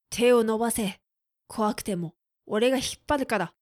クール男性
dansei_tewonobase.kowakutemooregahipparukara.mp3